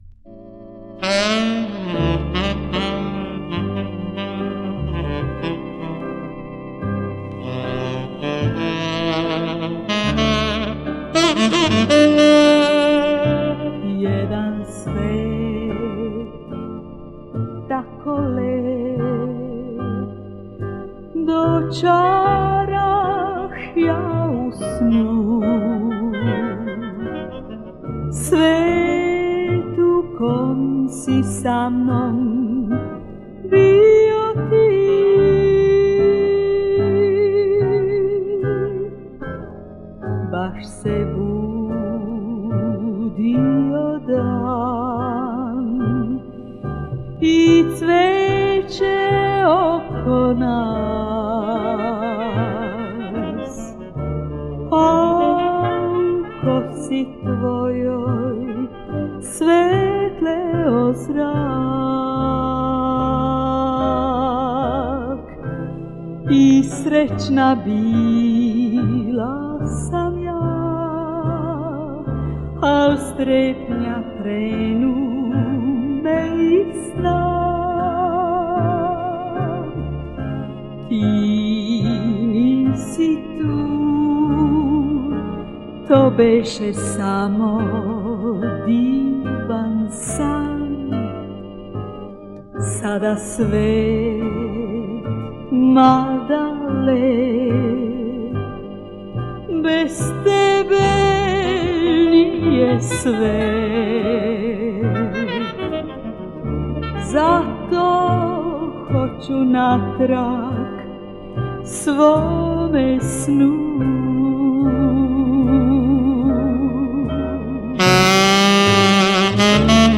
Версии с вокалом.